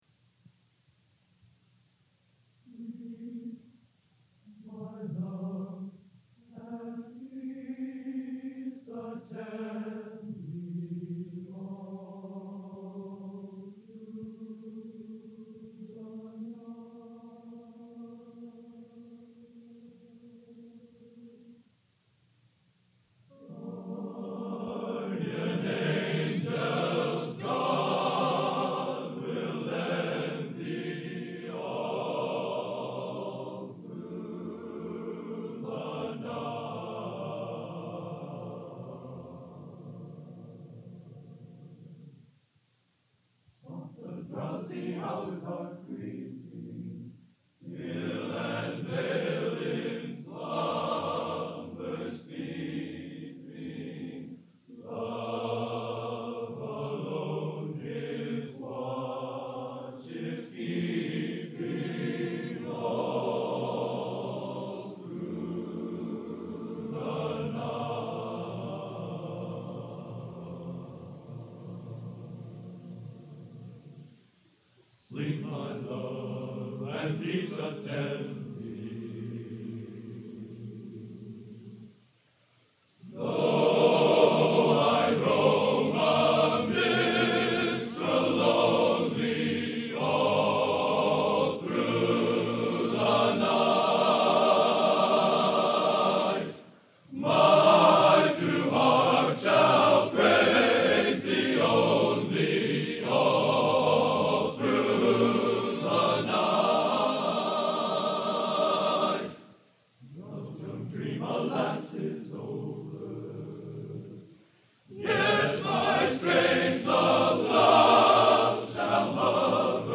Genre: A Cappella Traditional | Type: End of Season